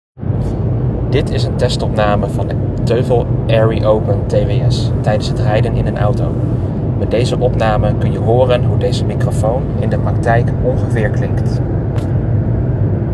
Waar ik ook erg over te spreken ben, is de opnamekwaliteit.
Er is geen ruis, geen echo, het werkt prima in de wind, of zelfs in een luidruchtige auto.